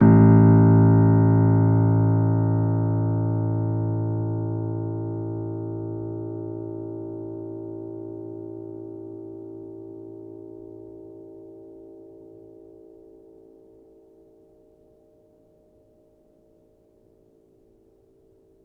healing-soundscapes/Sound Banks/HSS_OP_Pack/Upright Piano/Player_dyn2_rr1_006.wav at main